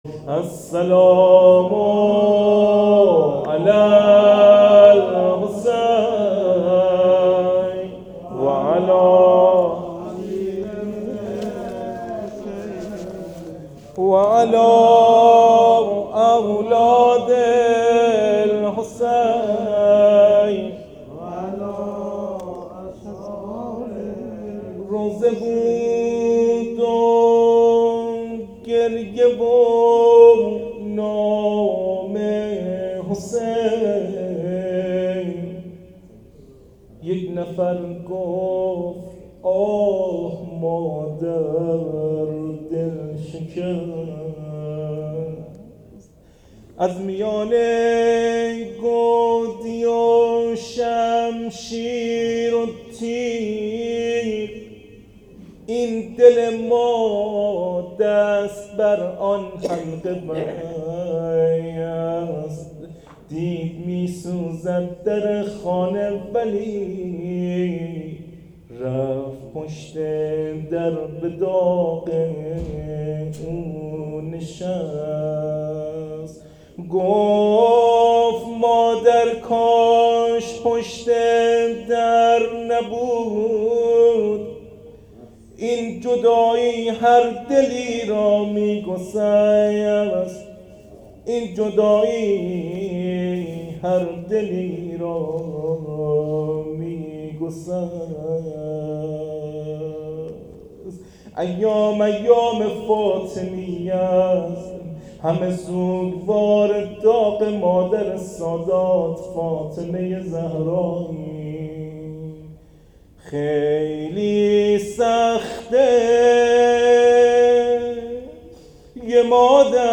روضه شب جمعه و شهادت حضرت زهرا س